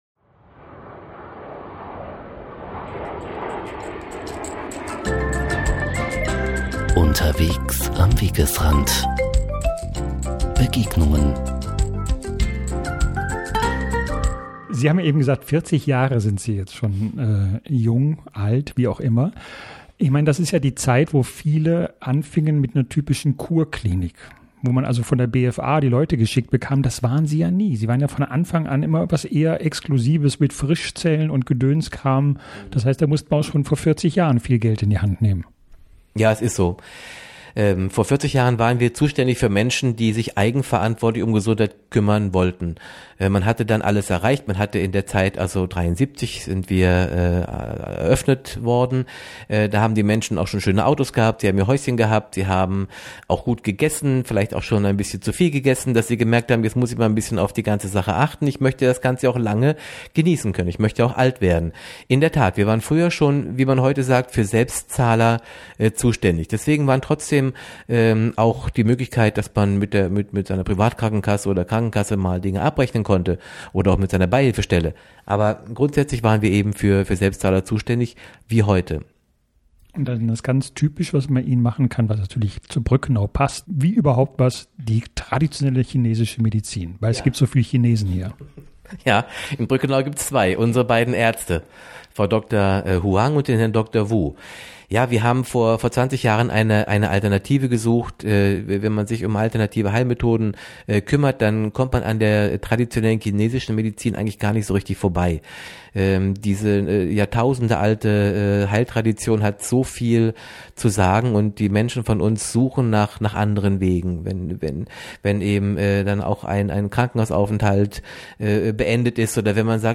Direktlink: Gespräch